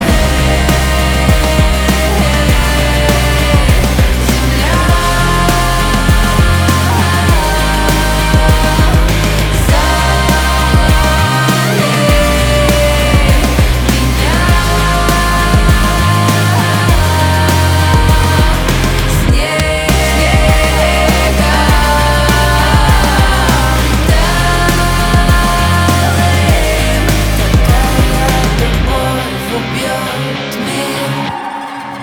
русский рок
барабаны , гитара